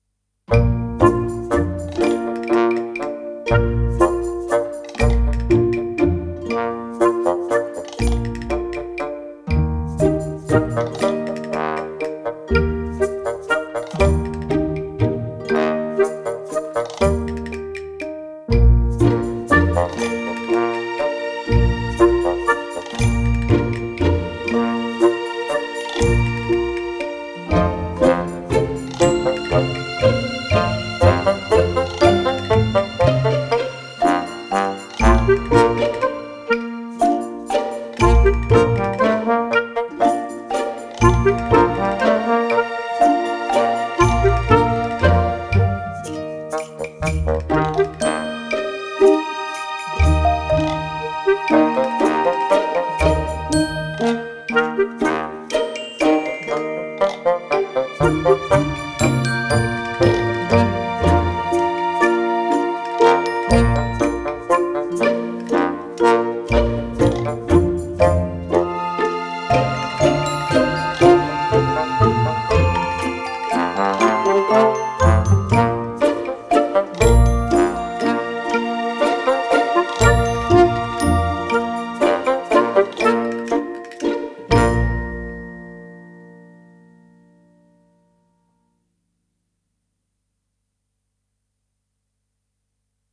Looped No Tempo 120 BPM
Tags Comedy